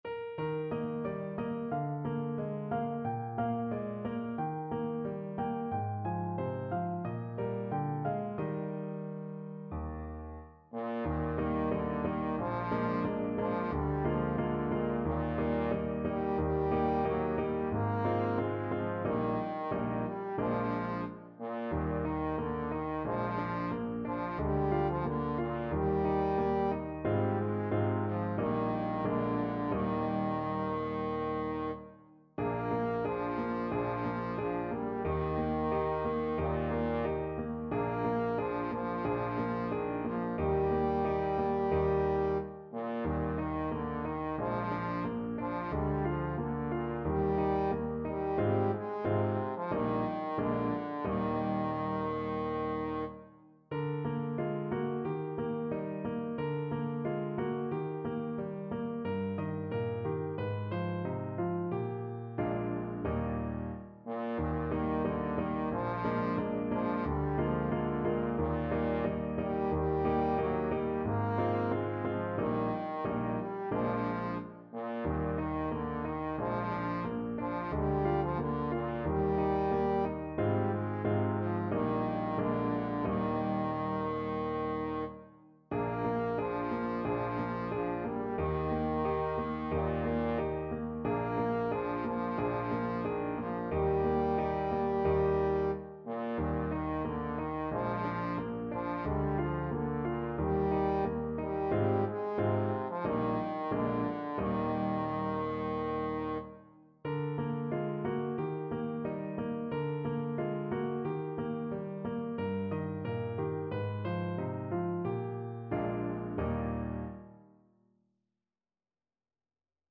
4/4 (View more 4/4 Music)
Andante, con espressione =90
Bb3-Bb4